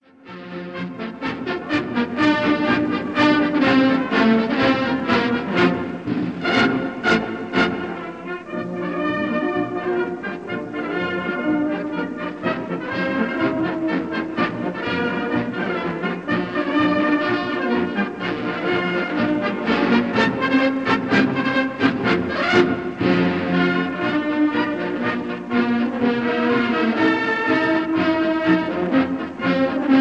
Massed Bands conducted by
Recorded live in the Crystal Palace, London